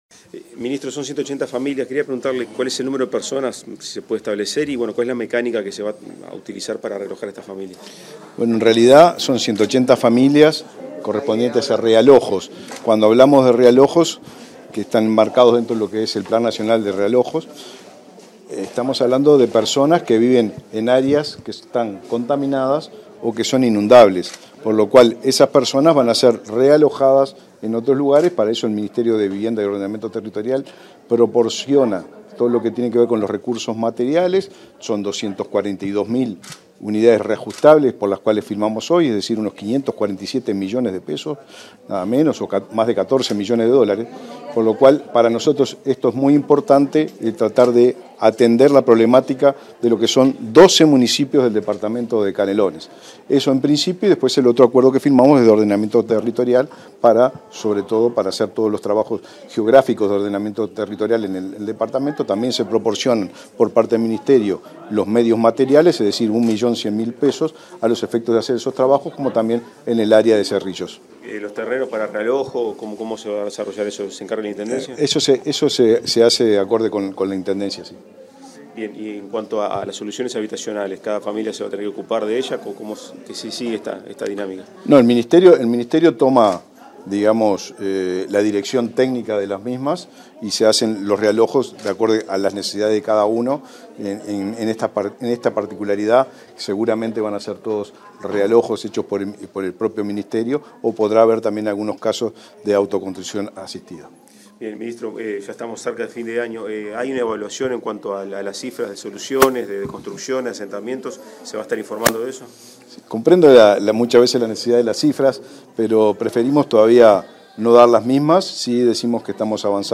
Declaraciones del ministro de Vivienda y Ordenamiento Territorial, Raúl Lozano
Declaraciones del ministro de Vivienda y Ordenamiento Territorial, Raúl Lozano 20/11/2023 Compartir Facebook X Copiar enlace WhatsApp LinkedIn Tras la firma de la modificación de un acuerdo con la Intendencia de Canelones para realojar a 180 familias que vivían en asentamientos, el ministro de Vivienda y Ordenamiento Territorial, Raúl Lozano, realizó declaraciones a la prensa.